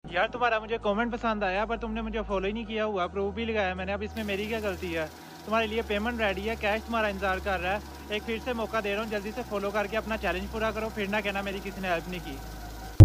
Rupees ₹ 🙄 🤑 Counting sound effects free download